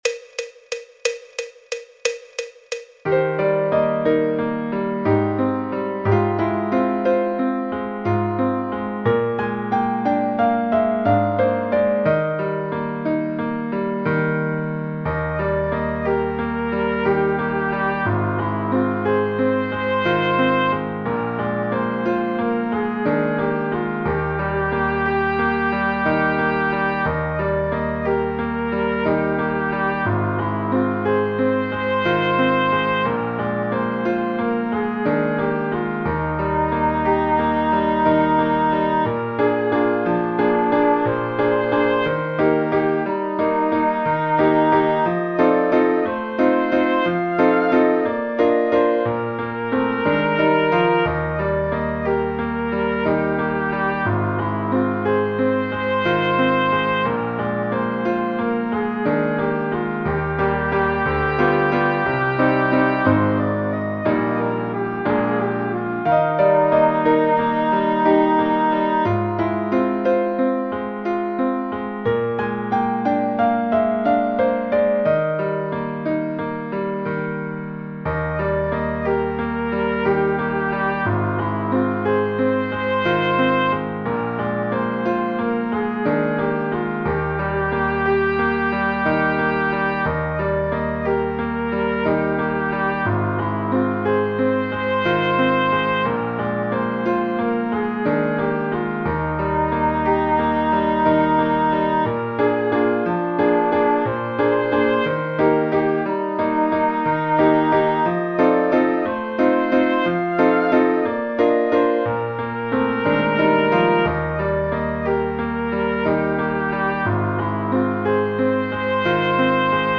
El MIDI tiene la base instrumental de acompañamiento.
Corno Inglés, Corno inglés
Música clásica